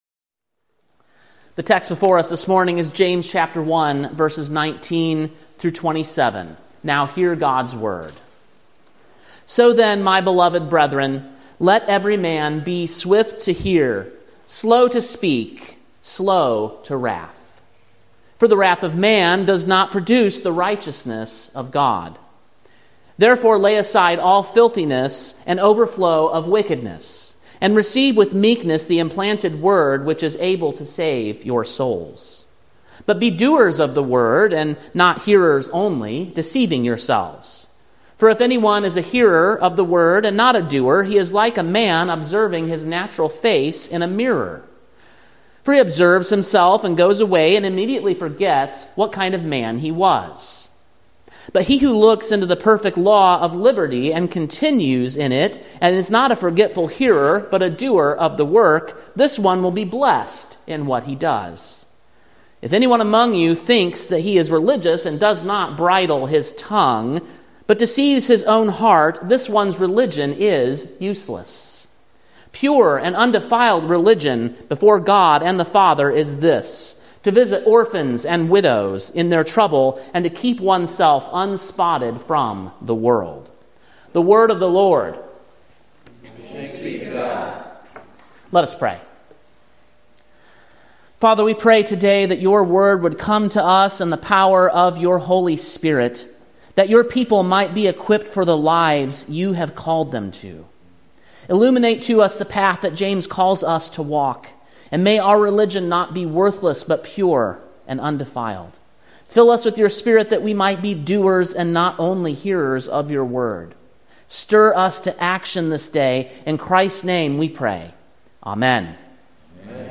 Sermon preached on July 28, 2024, at King’s Cross Reformed, Columbia, TN.